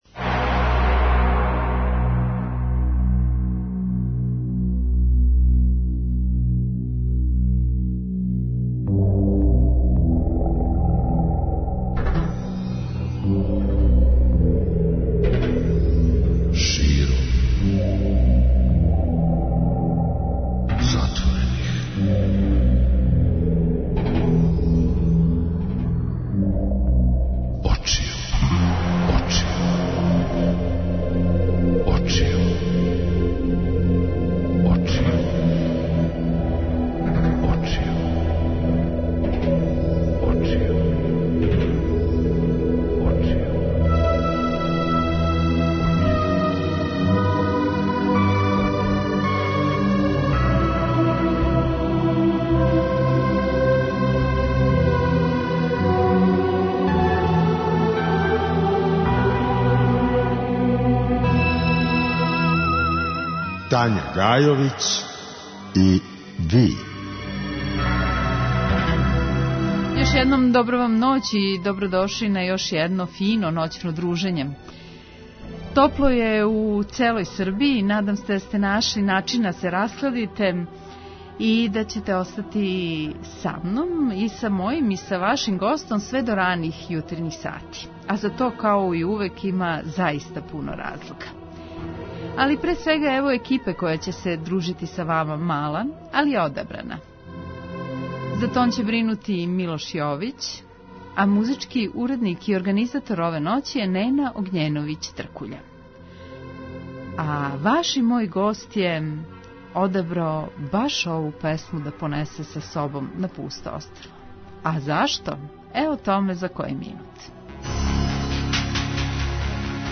Слушаоци ће, такође, имати прилике да чују и његове нове инструменталне композиције и сазнају чиме су инспирисане.